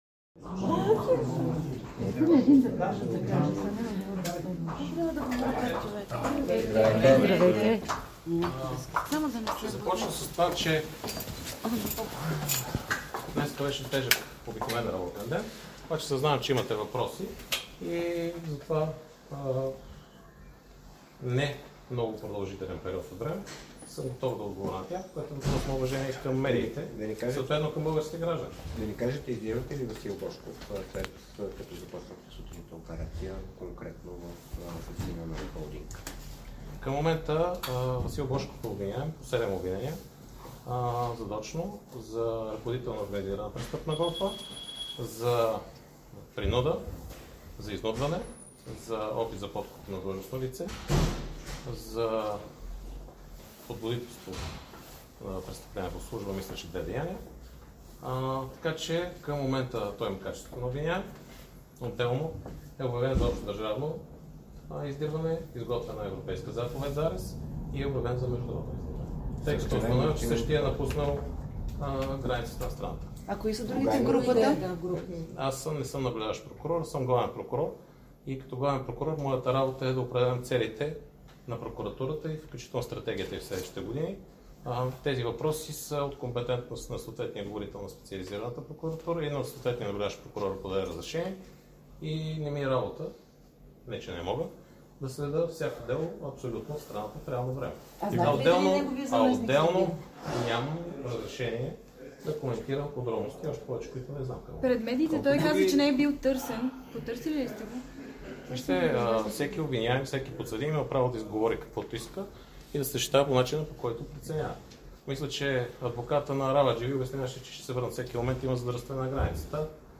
Изявление на Иван Гешев пред медиите за Васил Божков и Румен Радев